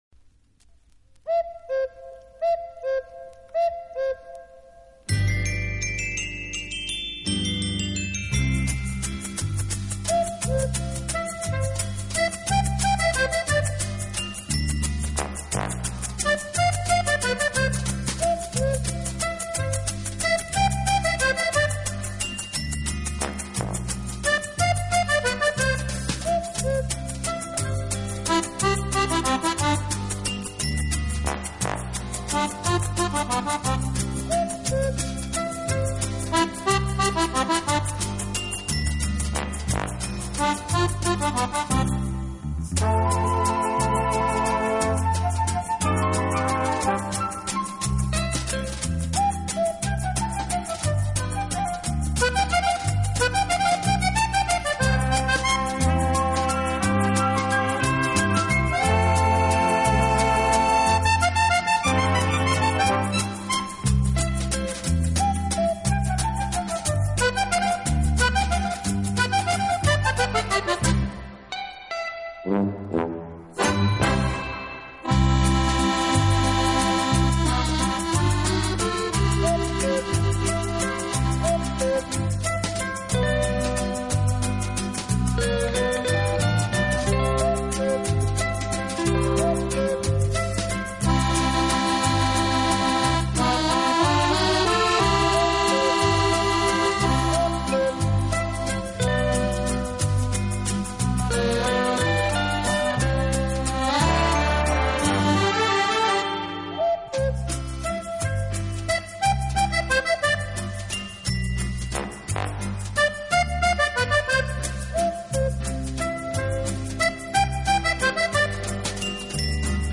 【手风琴专辑】